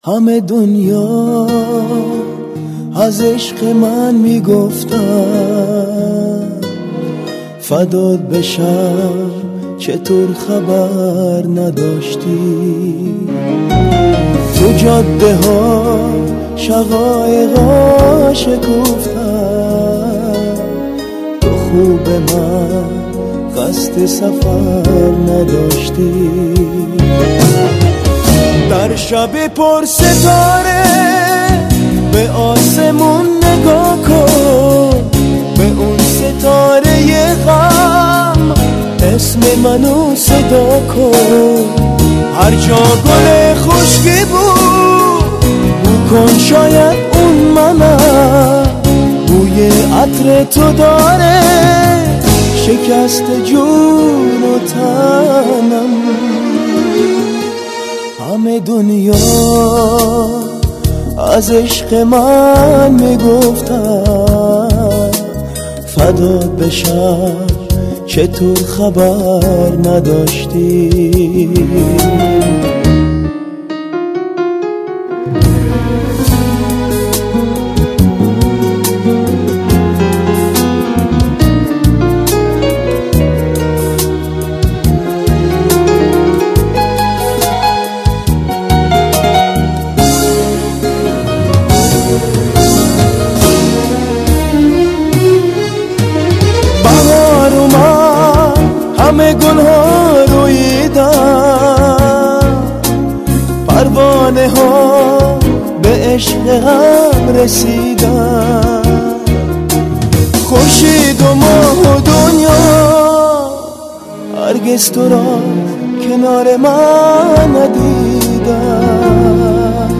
Мусиқа ва тарона